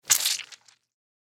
دانلود صدای حشره 13 از ساعد نیوز با لینک مستقیم و کیفیت بالا
جلوه های صوتی